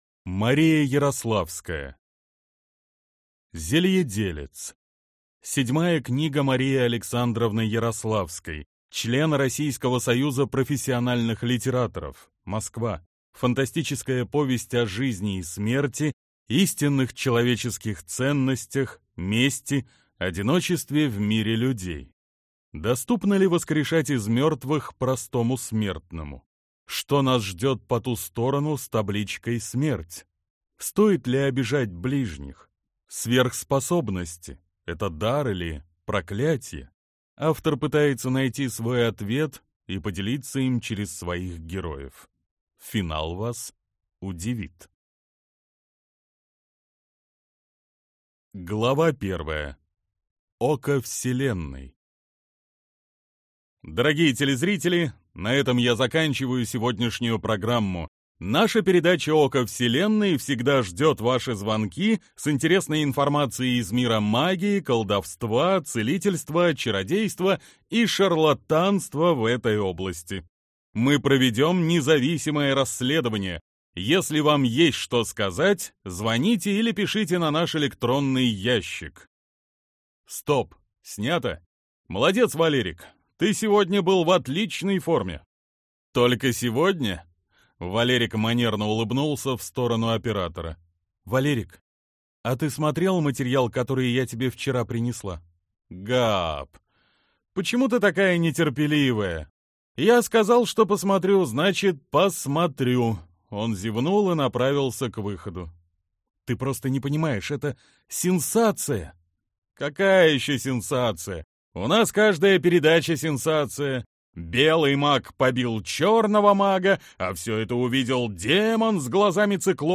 Аудиокнига Зельеделец | Библиотека аудиокниг